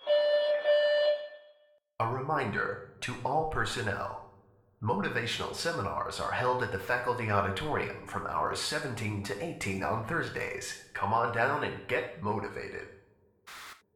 announcement3.ogg